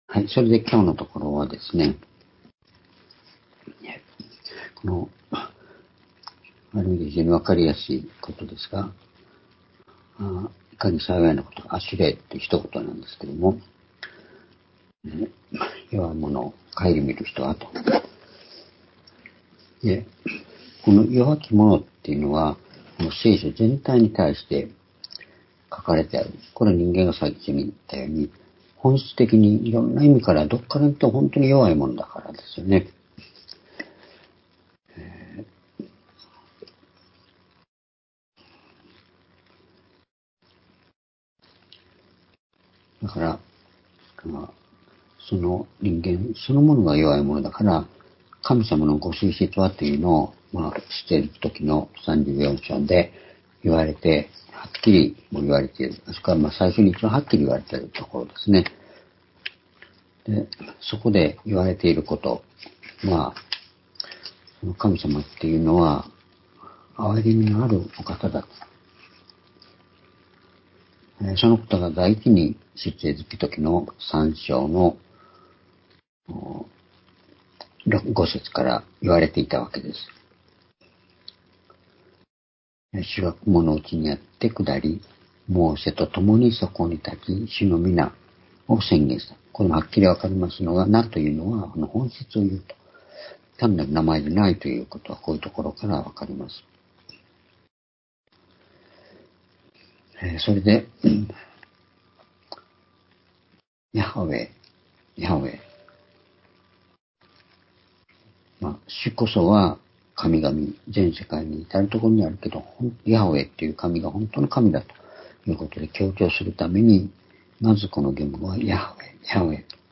（主日・夕拝）礼拝日時 2024年4月2日(夕拝) 聖書講話箇所 「弱きを顧みることの祝福」 詩編41編１節～１０節 ※視聴できない場合は をクリックしてください。